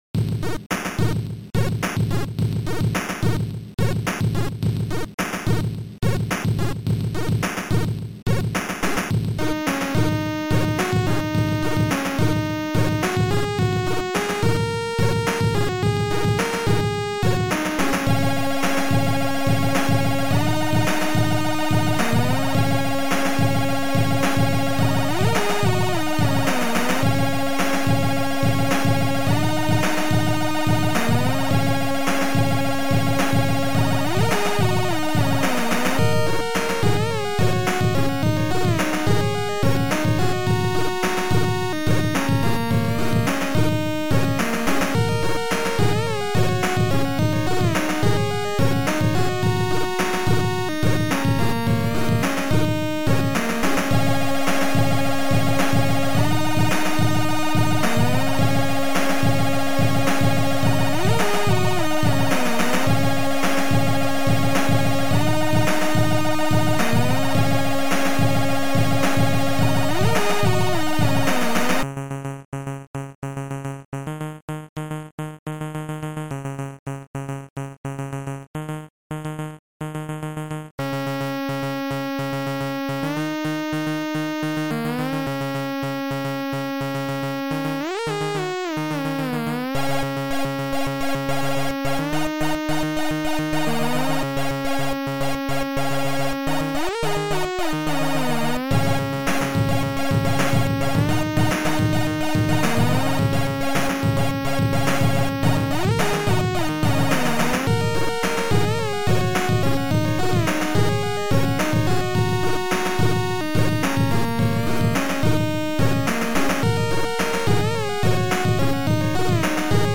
Chip Music Pack